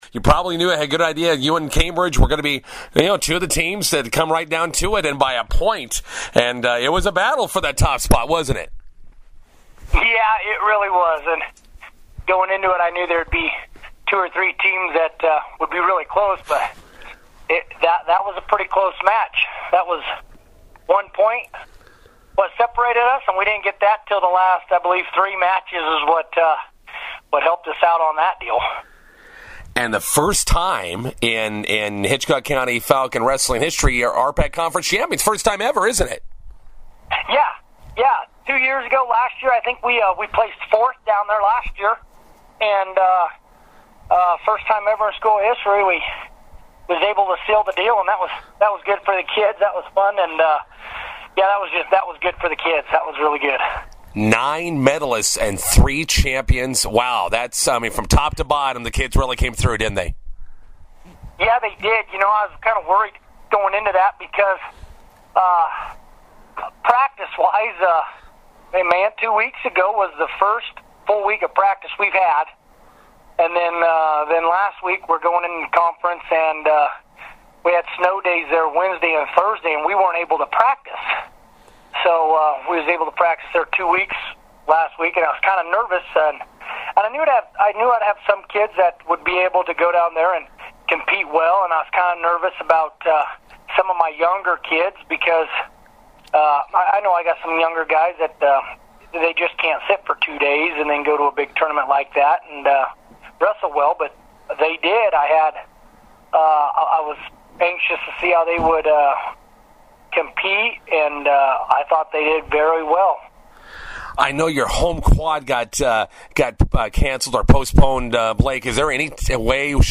INTERVIEW: Hitchcock County wrestling wins first-ever RPAC Conference tournament title.